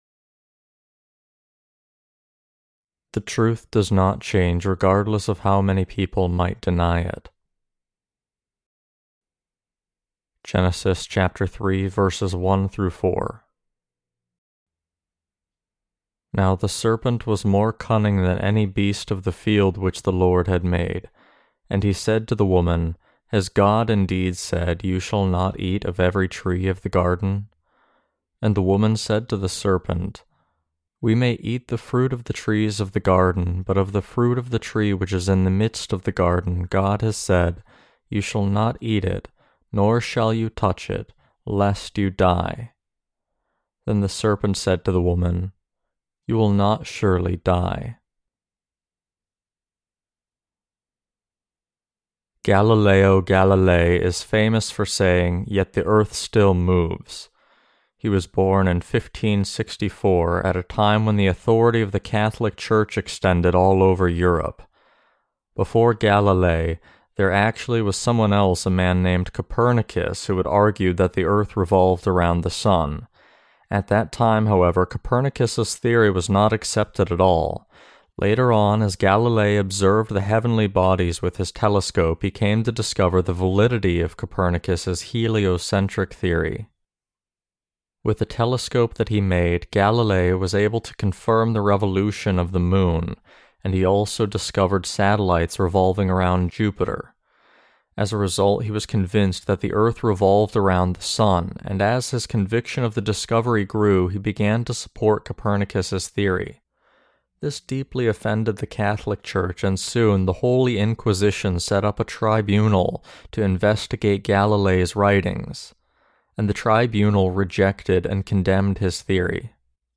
Sermons on Genesis (II) - The Fall of Man and The Perfect Salvation of God Ch3-1.